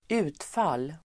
Uttal: [²'u:tfal:]